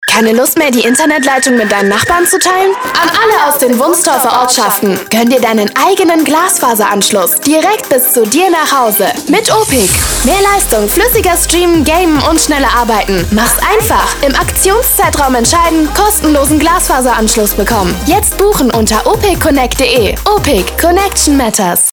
In die Arbeit vorm Mikrofon ist sie quasi hineingewachsen und wird auch heute noch mitunter als aufgeweckte junge "Kinderstimme" in der Werbung oder auch für Hörspiele oder Synchron gebucht.
Funk 2025